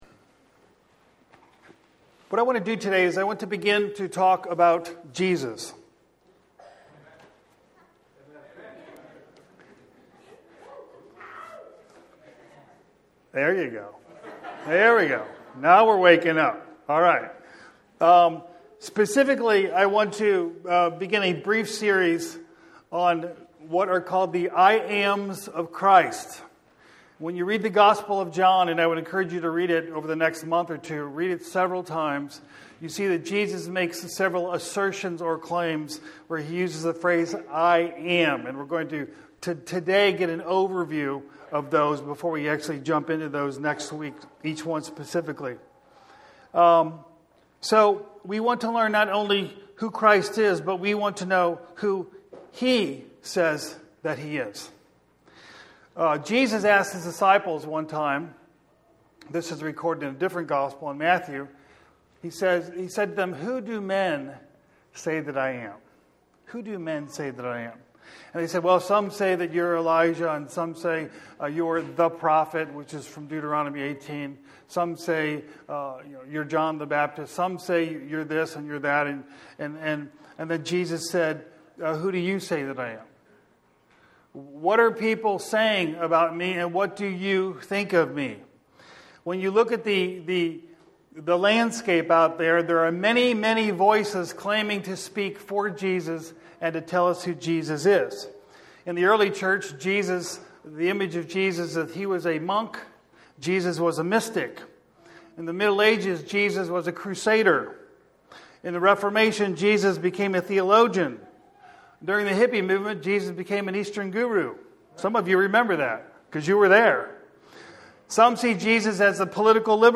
First sermon of a series on the I AM’s of Christ in the book of John.